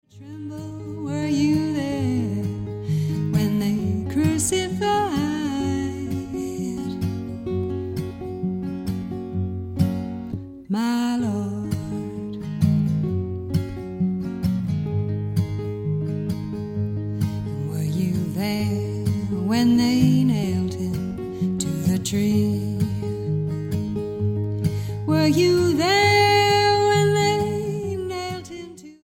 STYLE: Roots/Acoustic
1960s blues folk vein